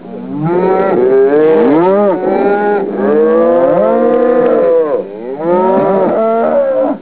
cow.wav